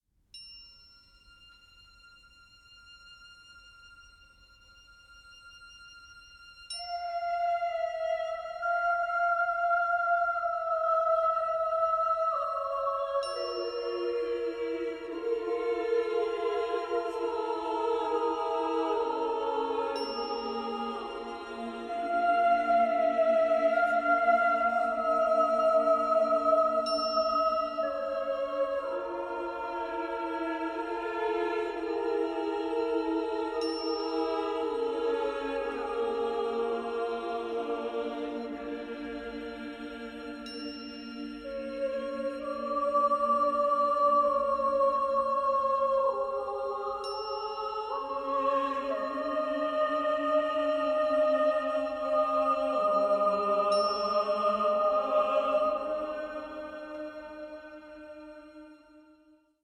26 Chorus 8